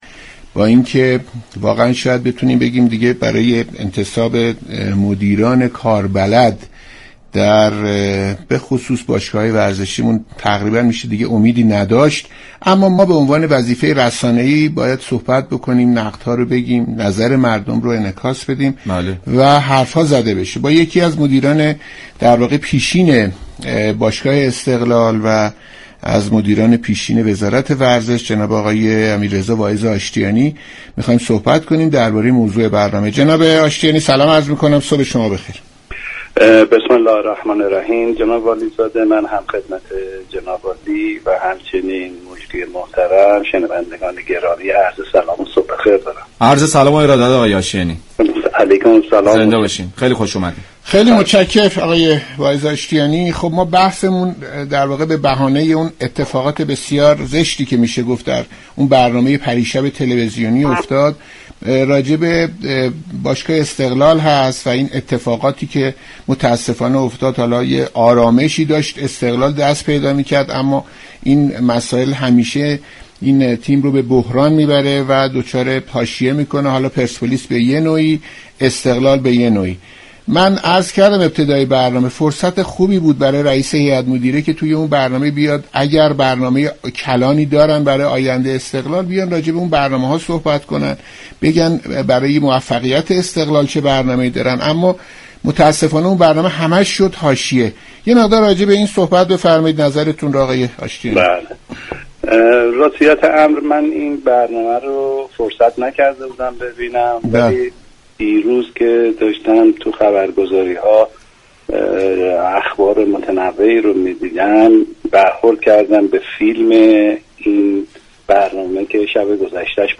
شما می توانید از طریق فایل صوتی پیوست بطور كامل شنونده این گفتگو باشید.